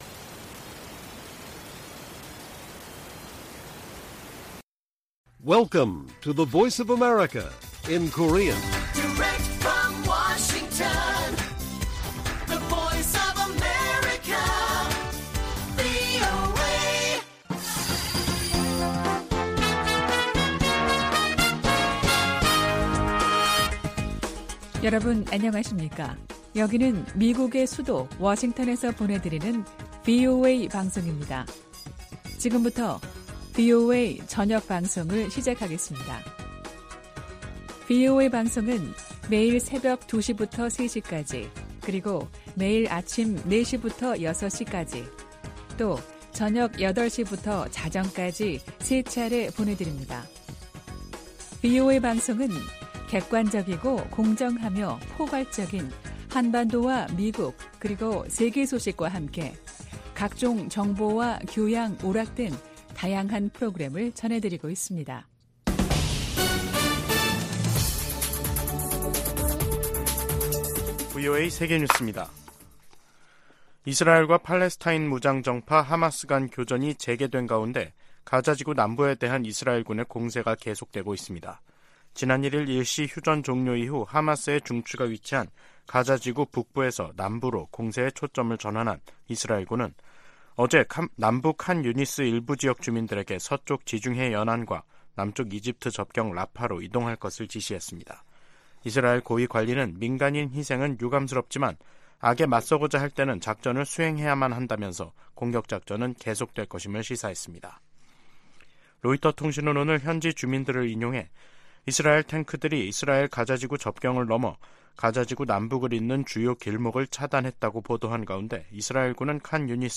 VOA 한국어 간판 뉴스 프로그램 '뉴스 투데이', 2023년 12월 5일 1부 방송입니다. 미국 정부가 남북한의 정찰 위성 발사에 대해 이중 기준을 가지고 있다는 북한의 주장을 일축했습니다. 국제 법학 전문가들도 북한이 정찰위성 발사에 대해 국제법적 정당성을 강변하는 것은 국제 규범 위반이라고 지적했습니다. 미 하원 군사위원회 부위원장이 북한 정찰위성 발사를 강력 규탄했습니다.